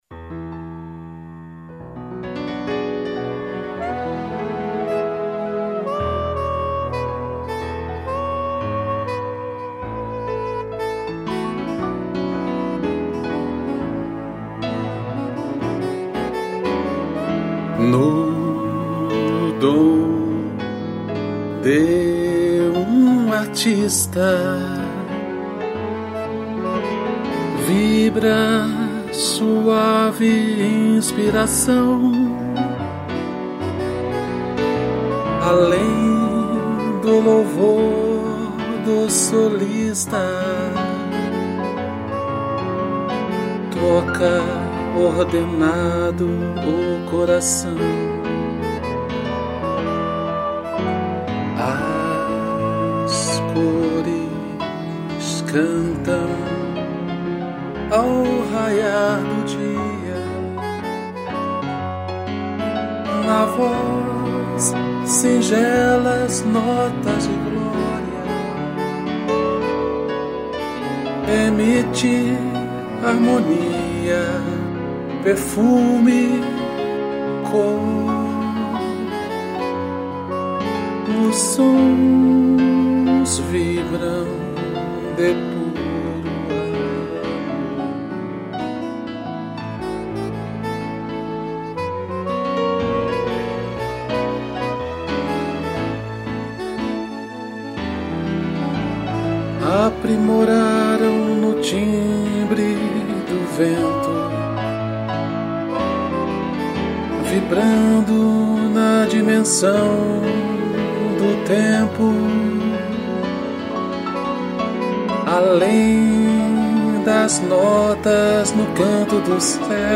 2 pianos, sax e cello